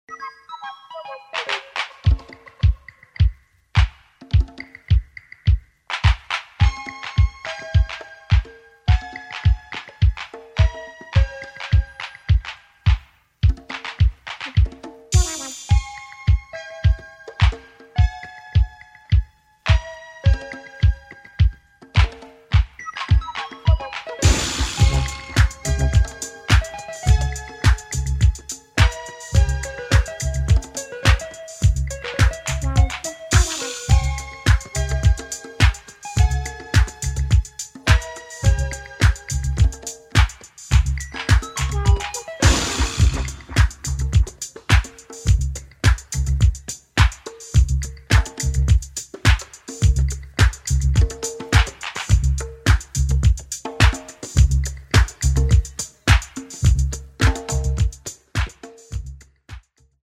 [ REGGAE / DUB / DISCO ]